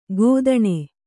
♪ gōdaṇe